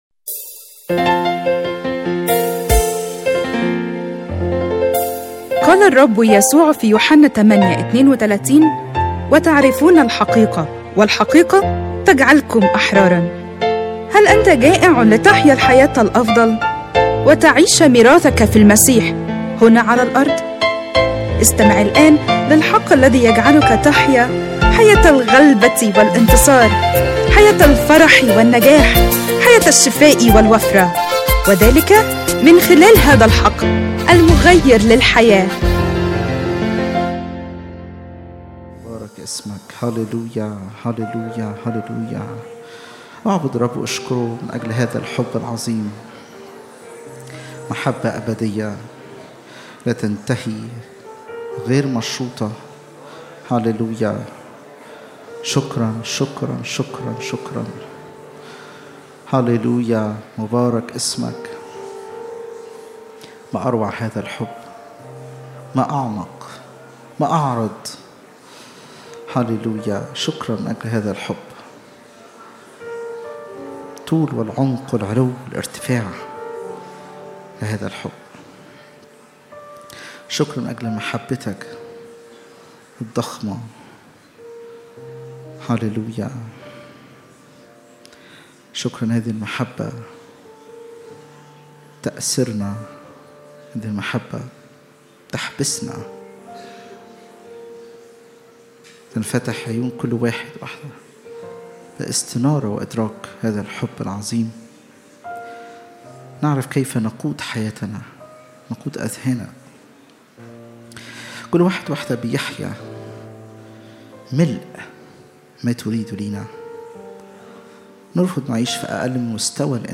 اجتماع الثلاثاء